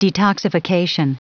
Prononciation du mot detoxification en anglais (fichier audio)
Prononciation du mot : detoxification